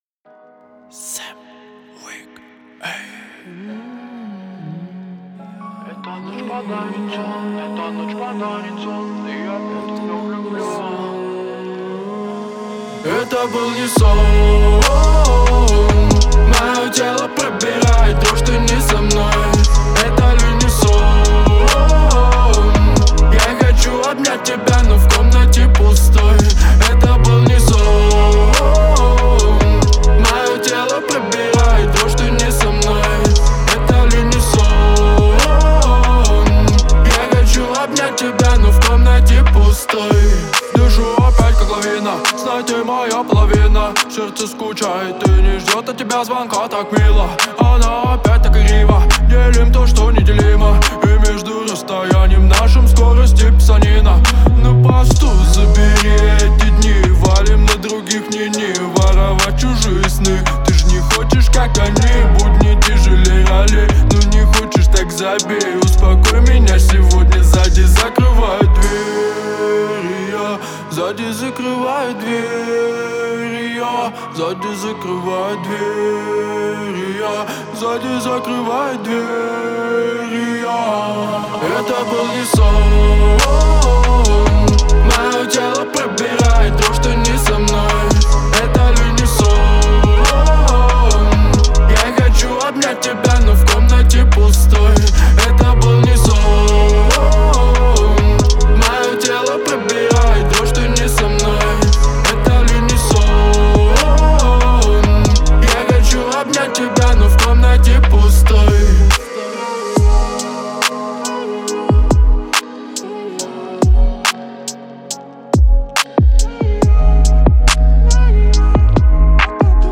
который сочетает в себе элементы поп и электронной музыки.